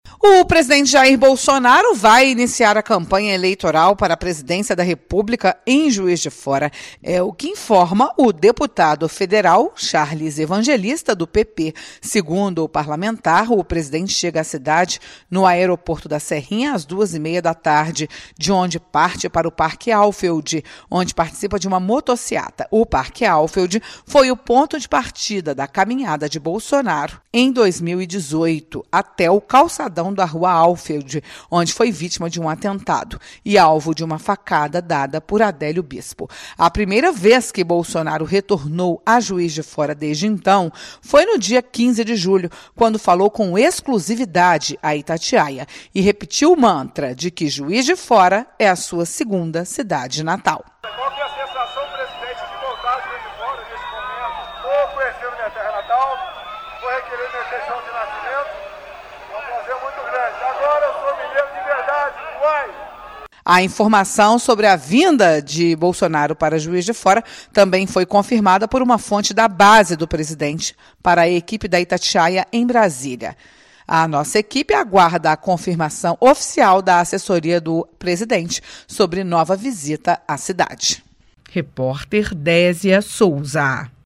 Confira com a repórter